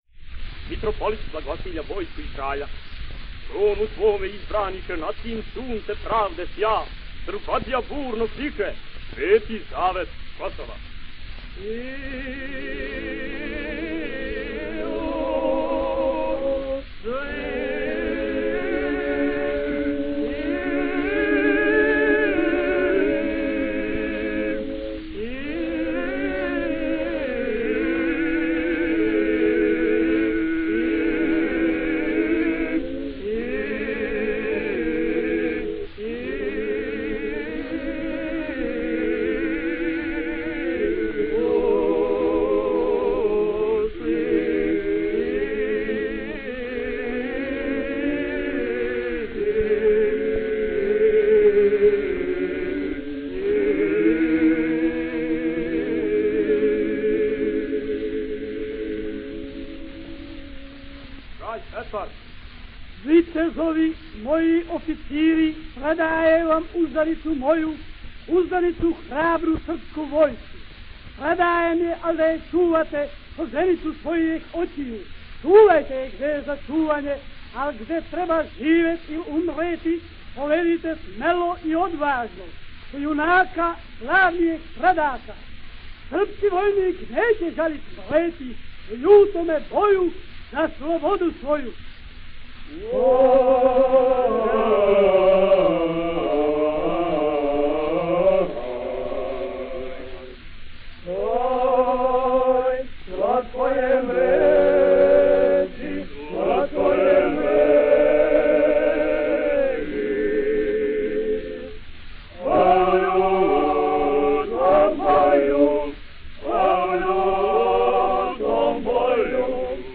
Kako dodaje, glumci u njima bili su deo tzv. akademske omladine.